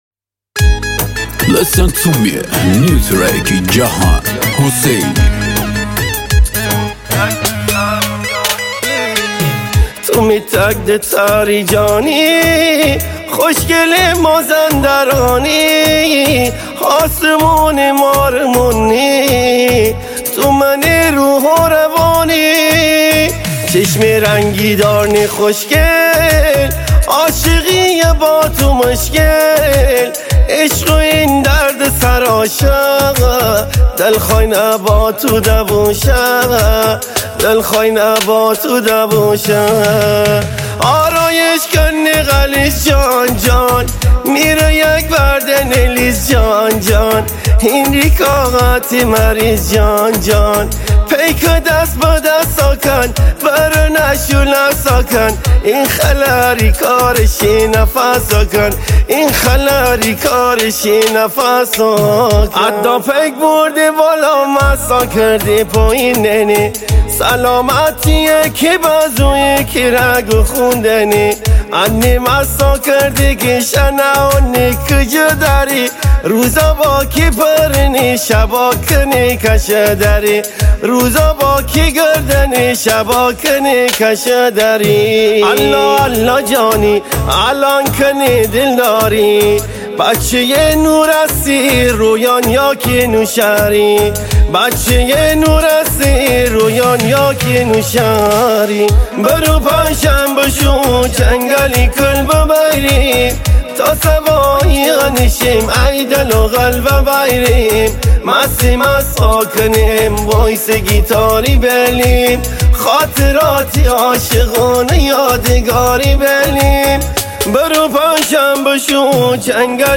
ریتمیک ( تکدست )
پخش و دانلود آهنگ ریمیکس ریتمیک